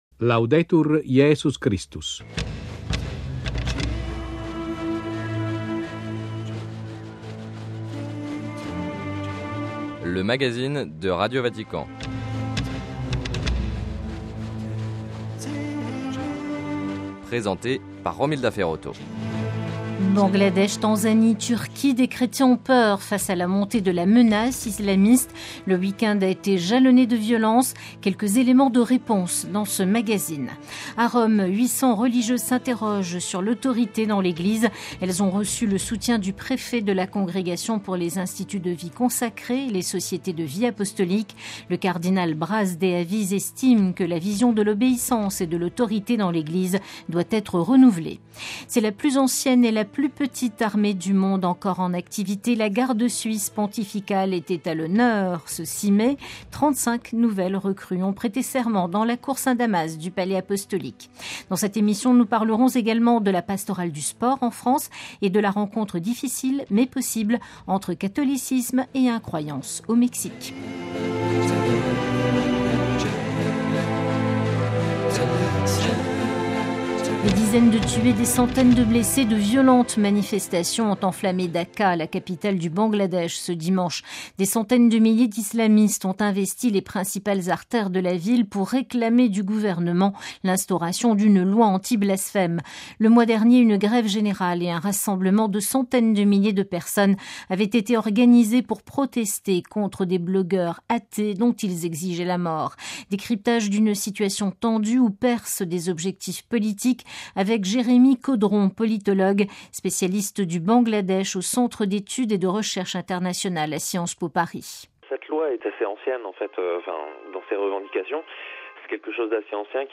Reportage. - Colloque chrétiens-bouddhistes à l'Université pontificale urbanienne.